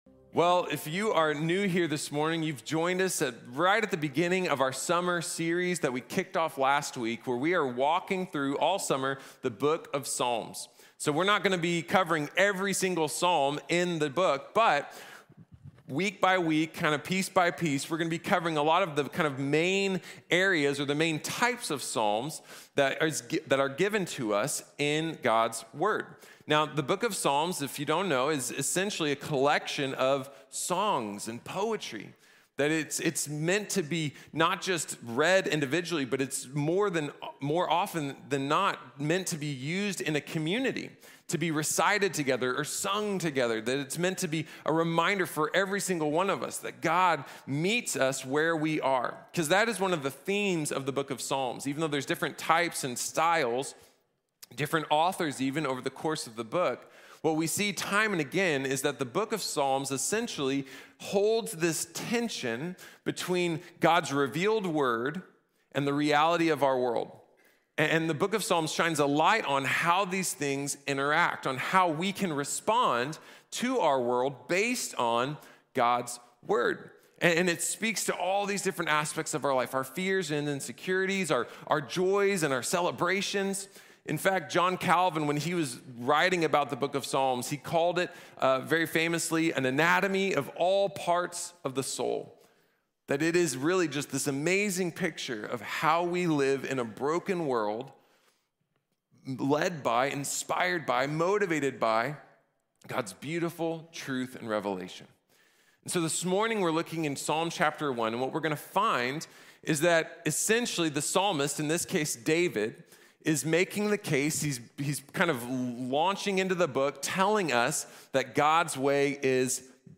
God's way is best | Sermon | Grace Bible Church